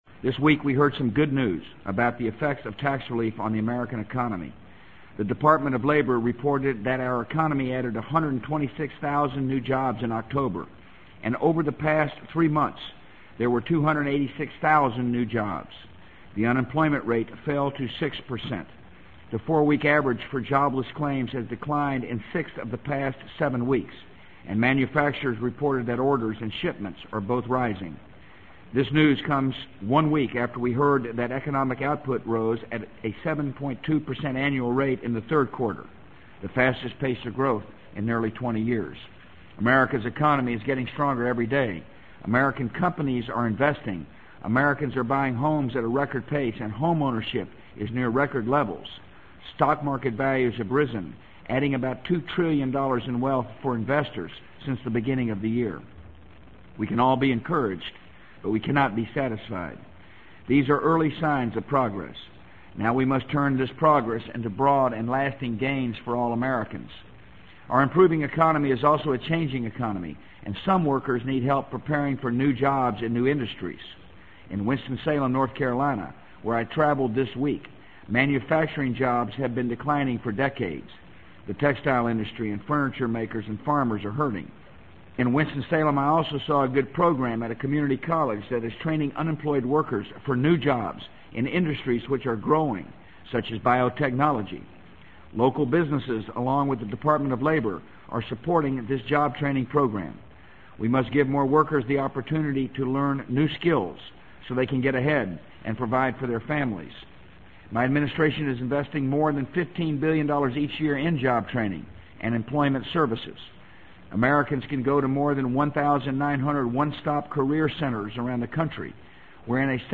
【美国总统George W. Bush电台演讲】2003-11-08 听力文件下载—在线英语听力室